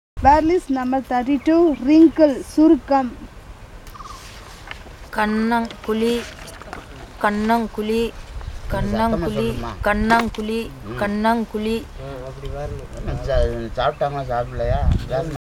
NotesThis is an elicitation of words about human body parts, using the SPPEL Language Documentation Handbook.